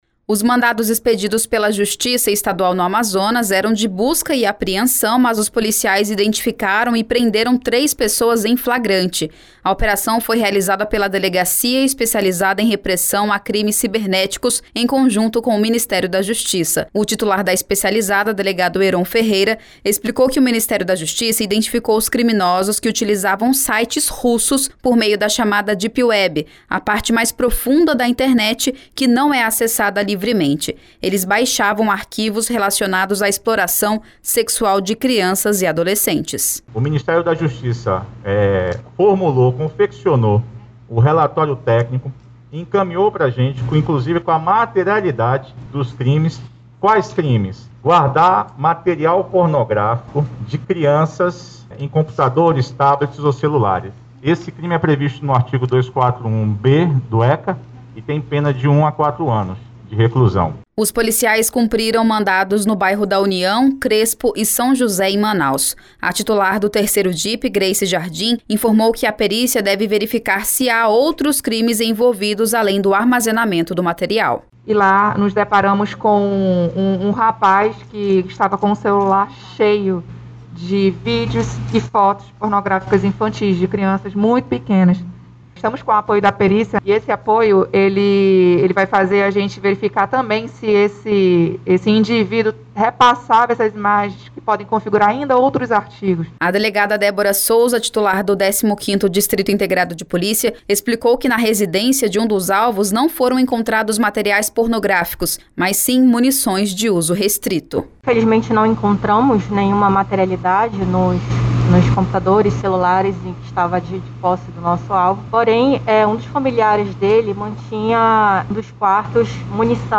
Entenda na reportagem: